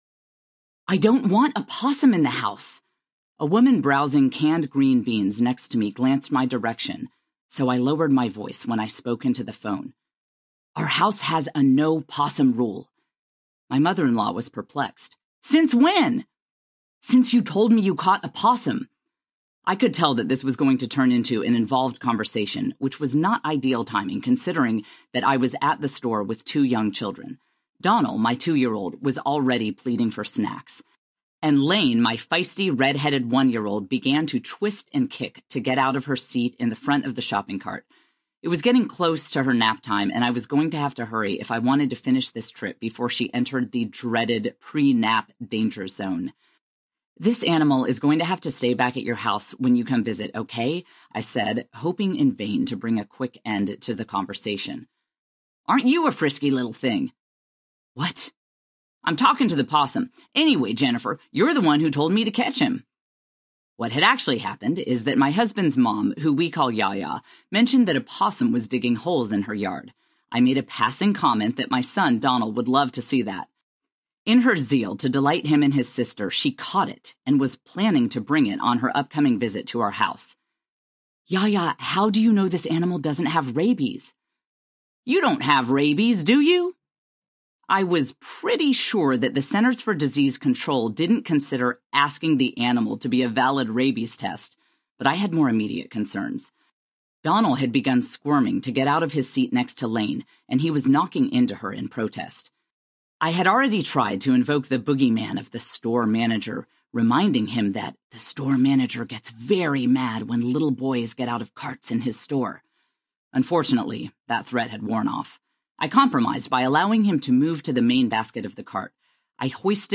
One Beautiful Dream Audiobook